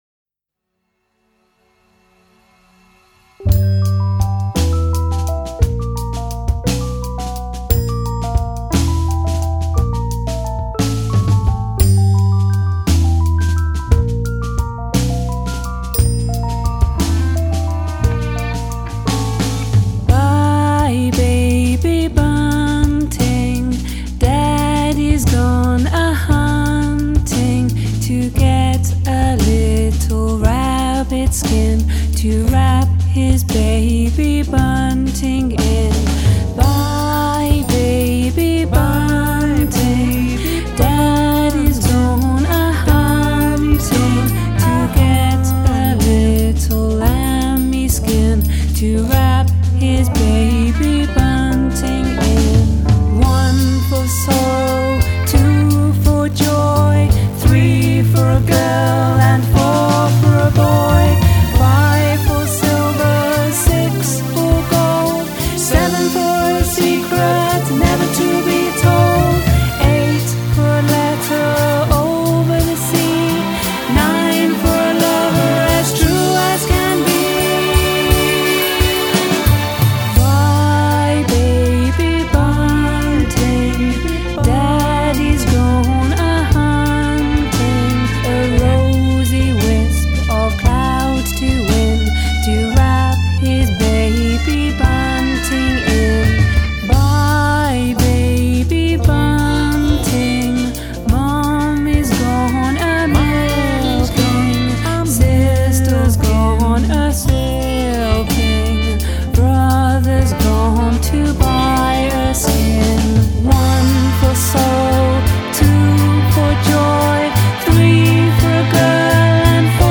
Lullaby